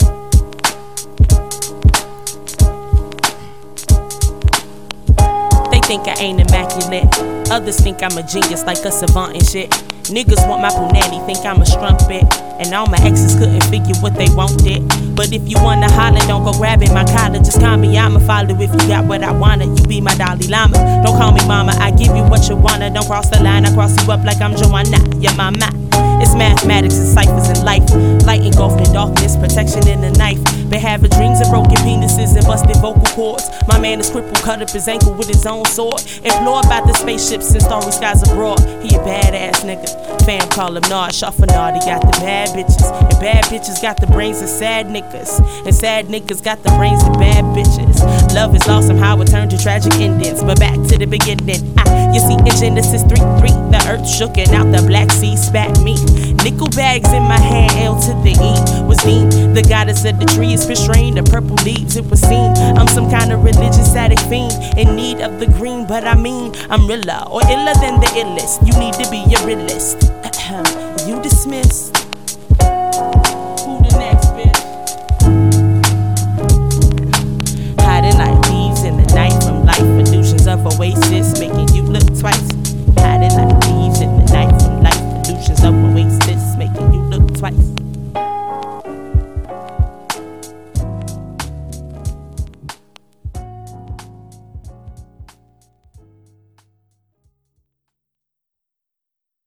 Brooklyn's Hip Hop duo